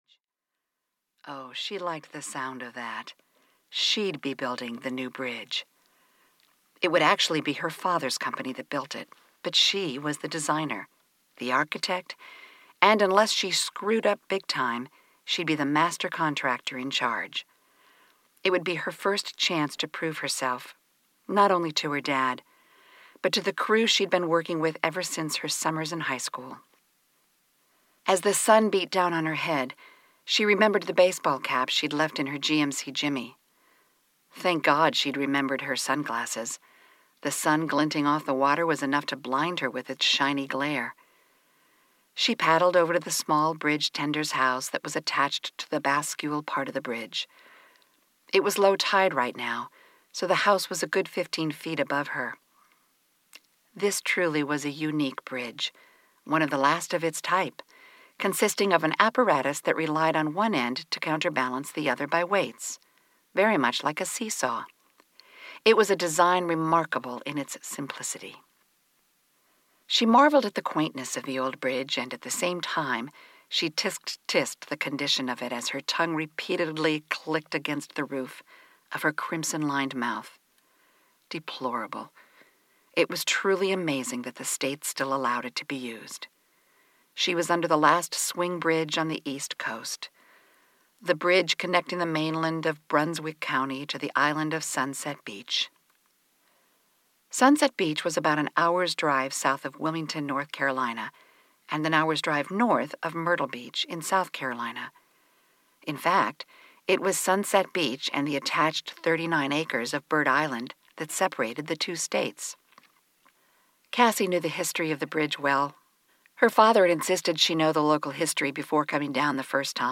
Audio knihaThe Secret of the Kindred Spirit (EN)
Ukázka z knihy